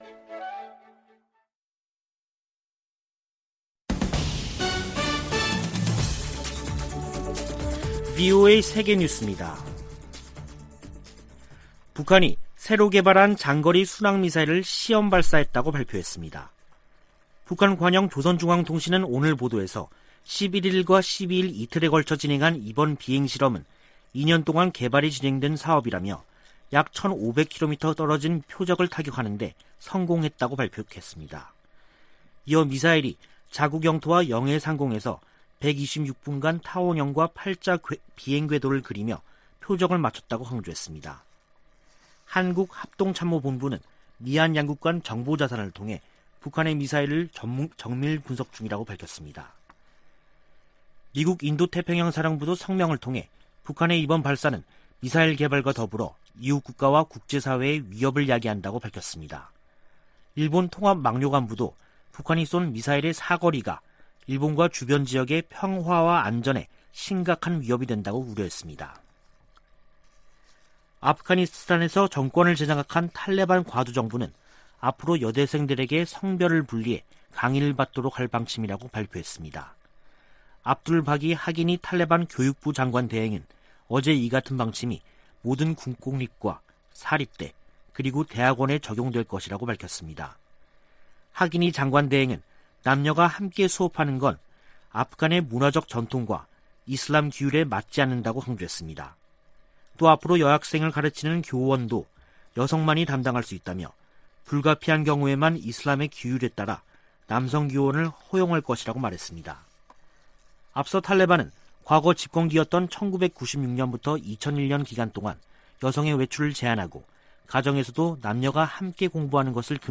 VOA 한국어 간판 뉴스 프로그램 '뉴스 투데이', 2021년 9월 13일 2부 방송입니다. 북한이 미-한 연합훈련 반발 담화를 낸 지 한 달 만에 신형 장거리 순항미사일을 시험발사했다고 밝혔습니다. 미 인도태평양사령부는 북한의 미사일 발사에 관해 계속 상황을 주시할 것이고, 동맹ㆍ협력국들과 긴밀히 협의하고 있다고 밝혔습니다. 미국 전문가들은 한국이 개발하는 잠수함들이 북한을 억제하는데 도움이 될 것이라고 전망했습니다.